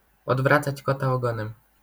wymowa:
IPA[ɔdˈvrat͡sat͡ɕ ˈkɔta ɔˈɡɔ̃nɛ̃m], AS[odvracać kota ogõnẽm], zjawiska fonetyczne: nazal.